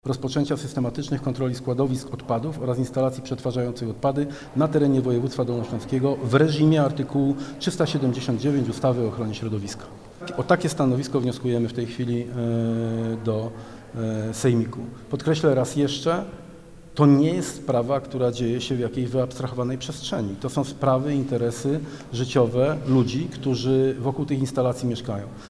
– Sejmik Województwa Dolnośląskiego wystosował apel do marszałka o natychmiastową kontrolę składowisk. Mówi Dariusz Stasiak – Radny Województwa Dolnośląskiego.